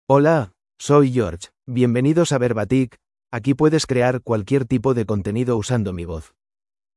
George — Male Spanish (Spain) AI Voice | TTS, Voice Cloning & Video | Verbatik AI
George is a male AI voice for Spanish (Spain).
Voice sample
Listen to George's male Spanish voice.
George delivers clear pronunciation with authentic Spain Spanish intonation, making your content sound professionally produced.